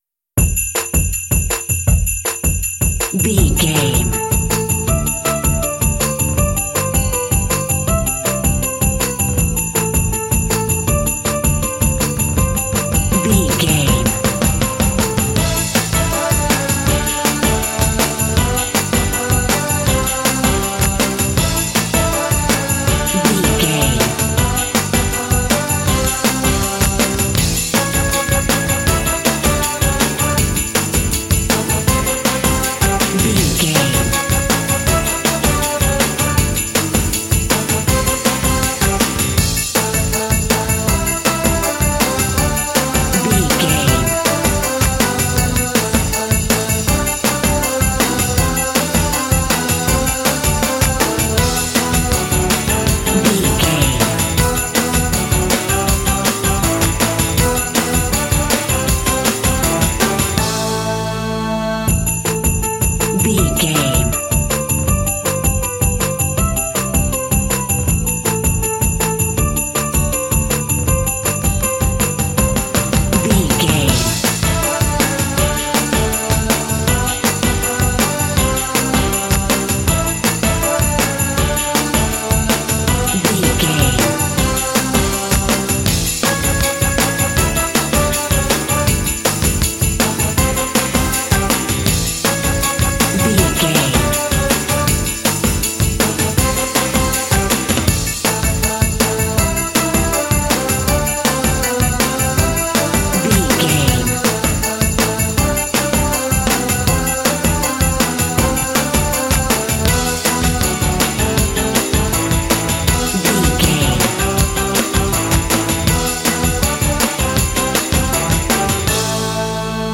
Ionian/Major
Fast
happy
uplifting
bouncy
festive
driving
synthesiser
electric piano
bass guitar
drums
contemporary underscore